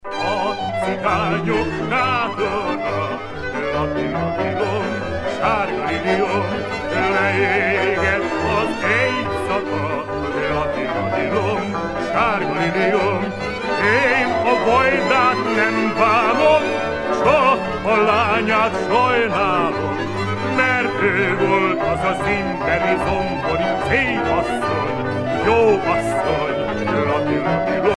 danse : csárdás (Hongrie)
Pièce musicale éditée